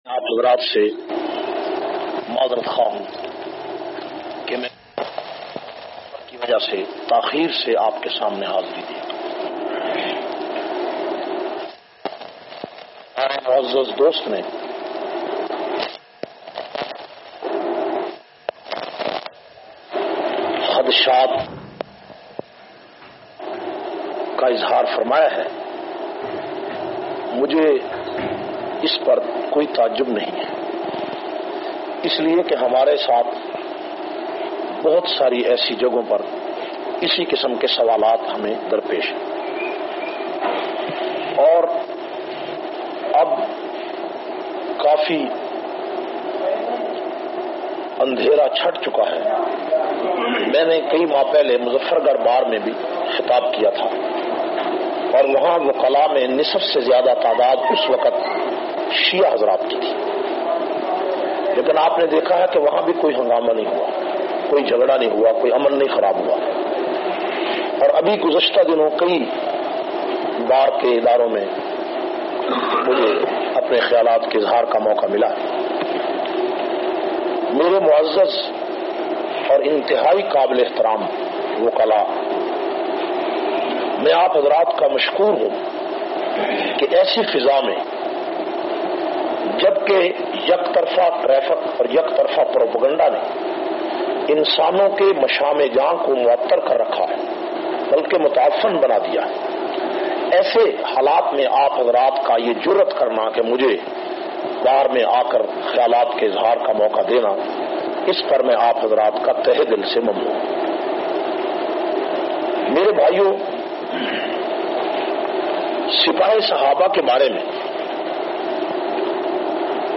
229- Wokala se khittab Laiyyah.mp3